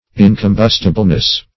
incombustibleness - definition of incombustibleness - synonyms, pronunciation, spelling from Free Dictionary
In`com*bus"ti*ble*ness, n. -- In`com*bus"ti*bly, adv.
incombustibleness.mp3